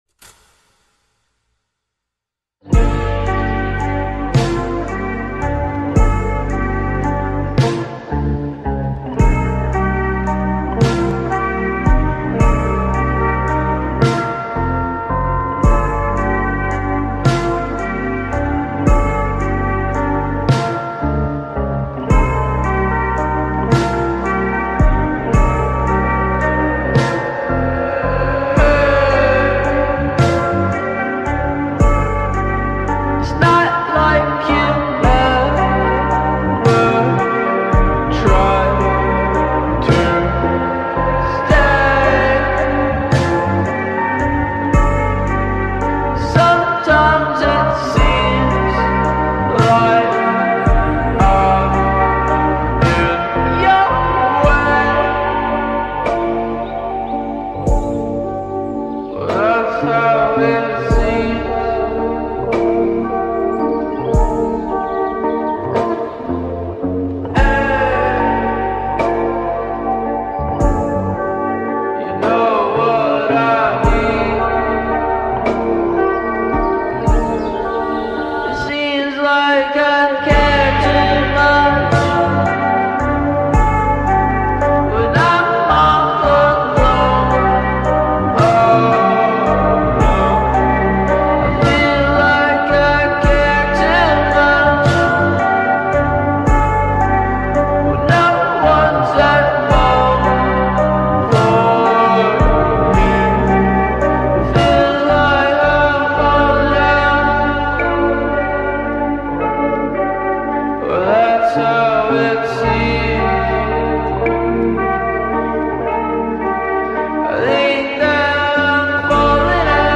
slowed + reverbed